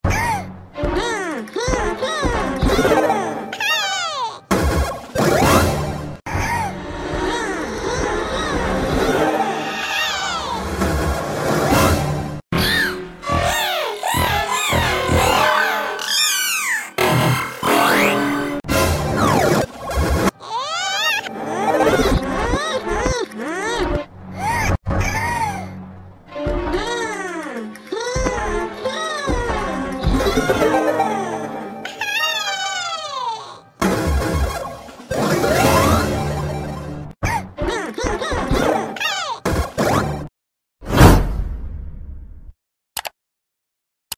5 Om Nom and Nibble sound effects free download
5 Om Nom and Nibble Nom "Growing" Sound Variations in 44 Seconds